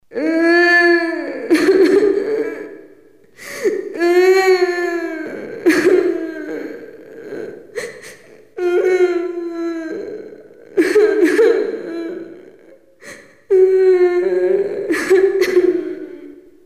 Звуки страха, криков